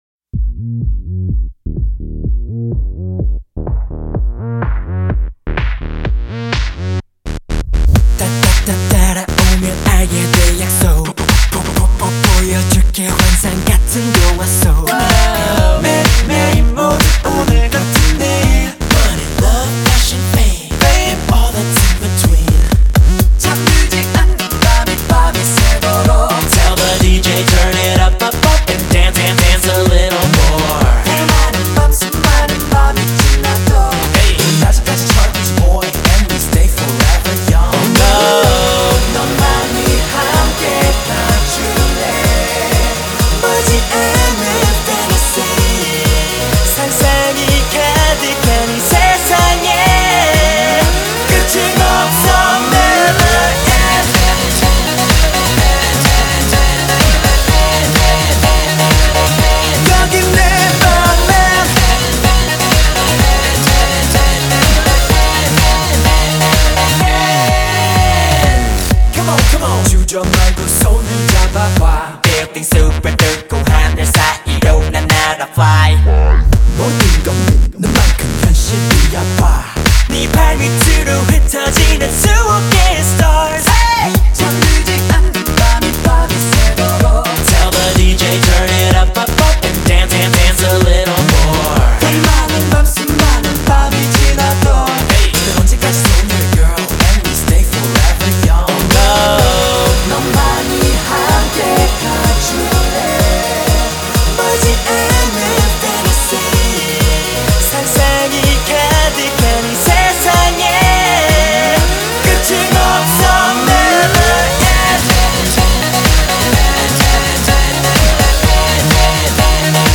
хочу вам дать послушать корейскую попсу